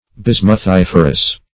Search Result for " bismuthiferous" : The Collaborative International Dictionary of English v.0.48: Bismuthiferous \Bis`muth*if"er*ous\, a. [Bismuth + -ferous.] Containing bismuth.